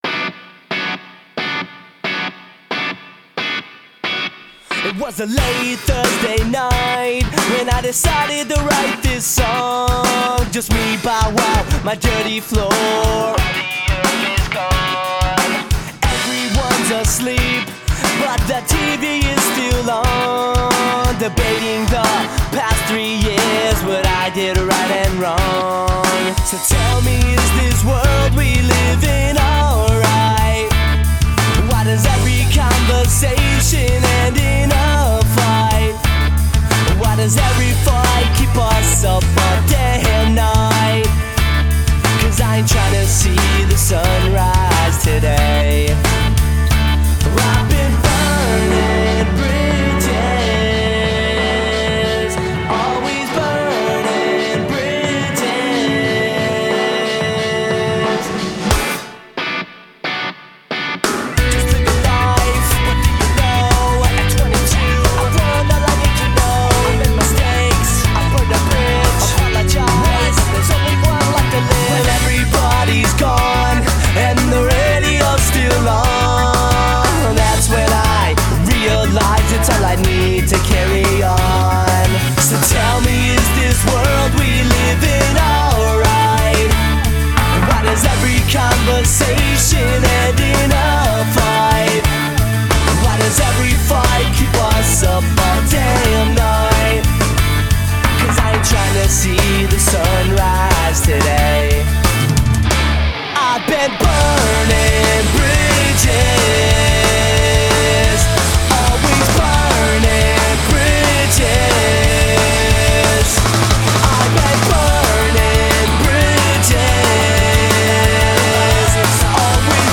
BPM90-180
MP3 QualityMusic Cut